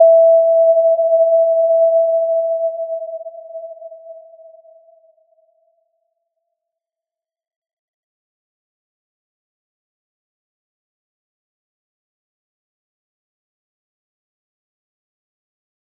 Little-Pluck-E5-p.wav